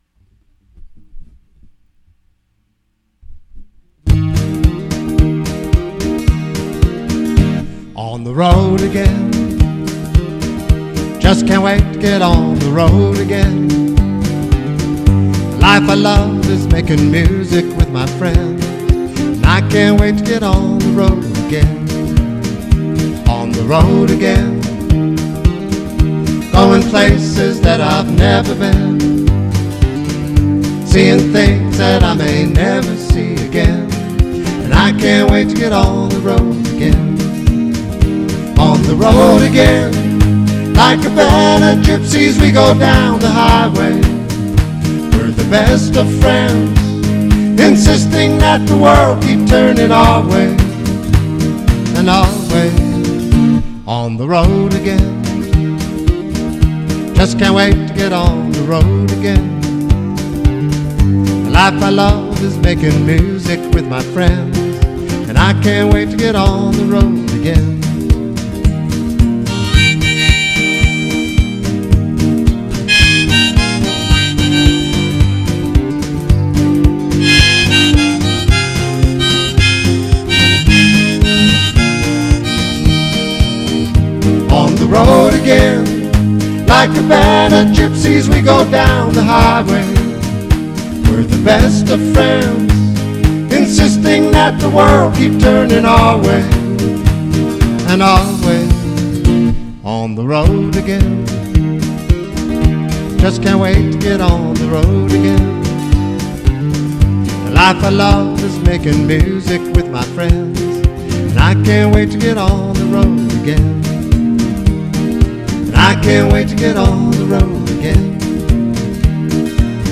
(recorded just how you would hear them live)